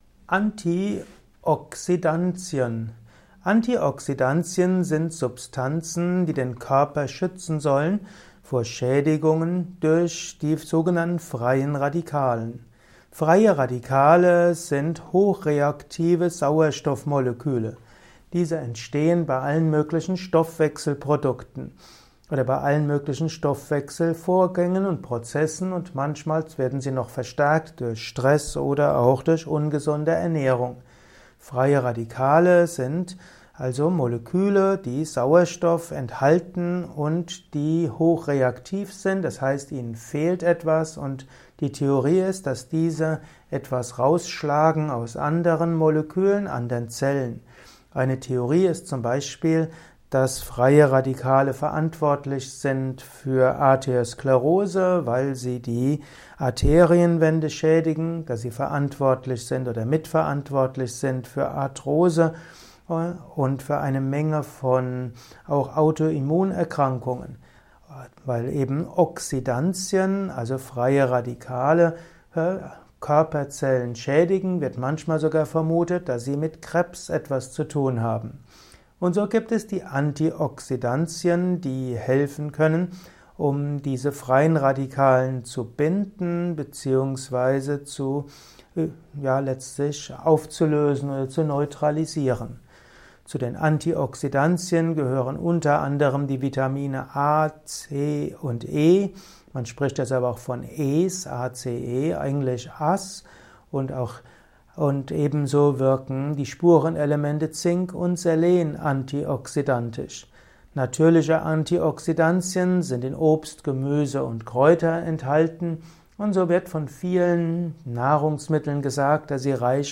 Informationen zu Antioxidantien in diesem Kurzvortrag